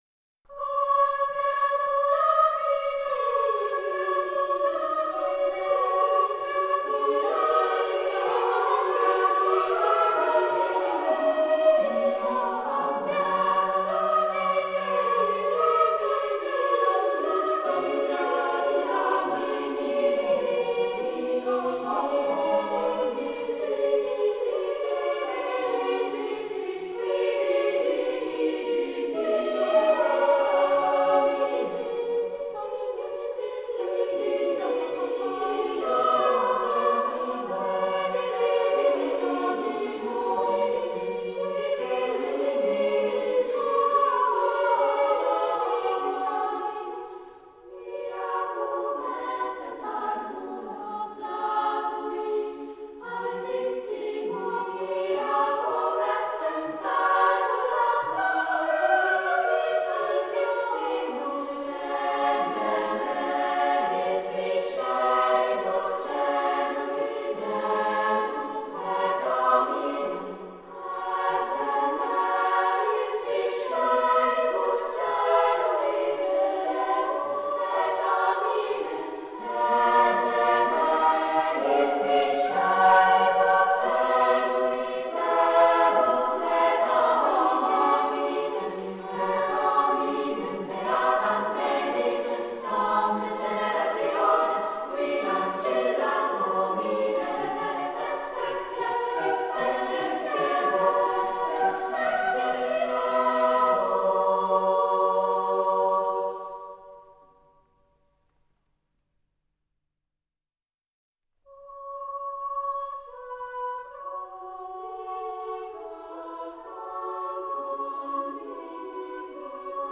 musica corale